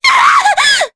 Maria-Vox_Damage_jp_03.wav